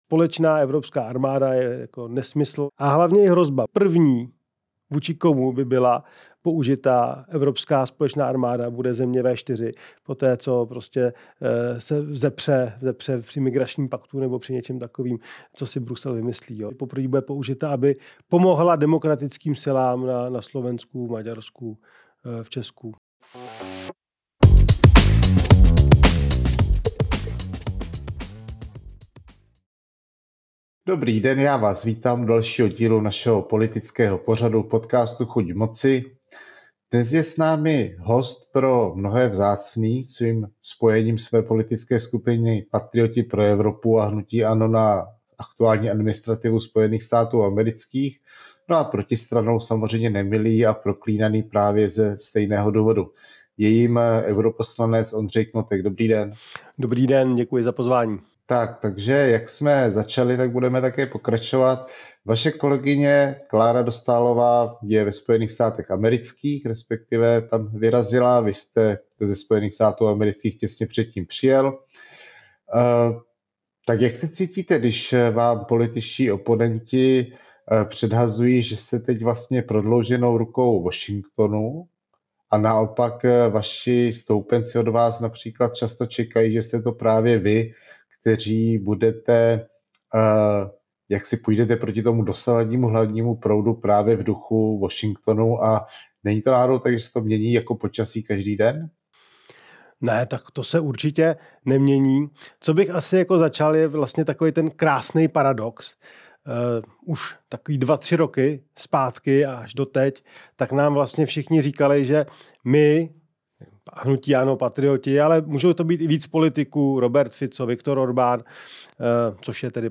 Natočeno ve studiu Datarun!